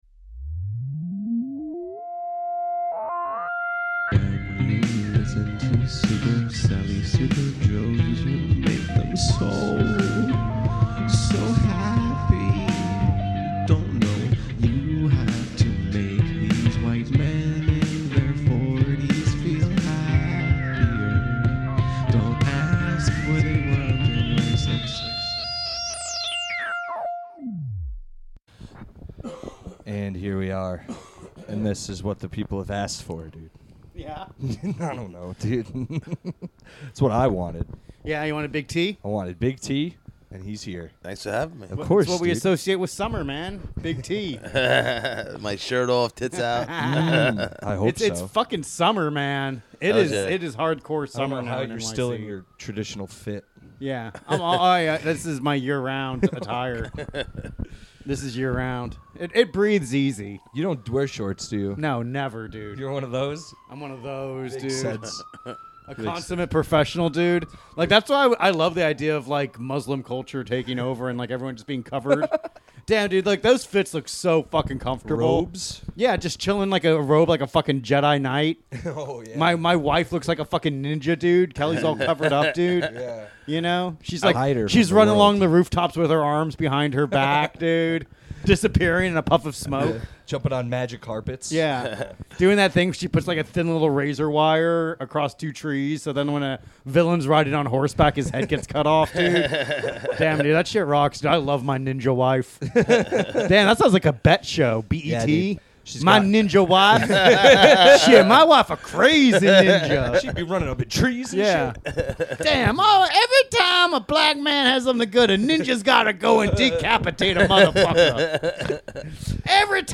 We chatted about comic books as only two friends could.